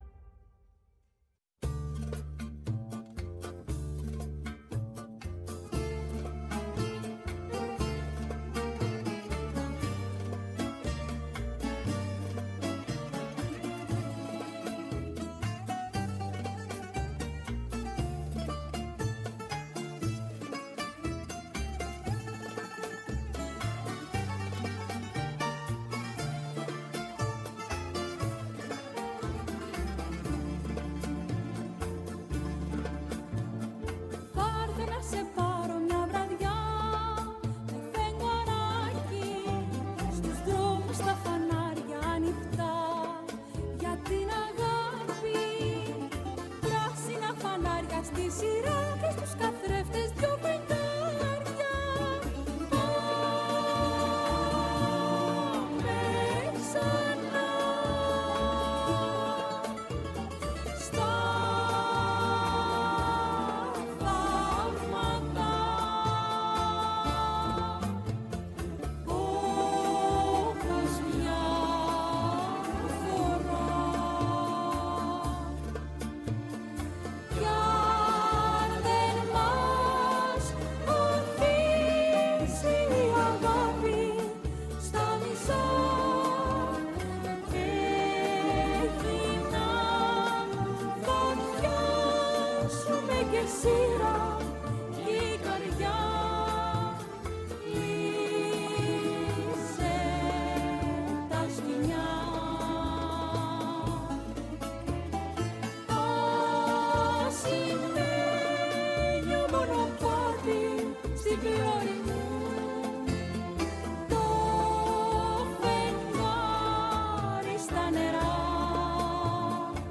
Η ΦΩΝΗ ΤΗΣ ΕΛΛΑΔΑΣ Κουβεντες Μακρινες ΟΜΟΓΕΝΕΙΑ Πολιτισμός ΣΥΝΕΝΤΕΥΞΕΙΣ Συνεντεύξεις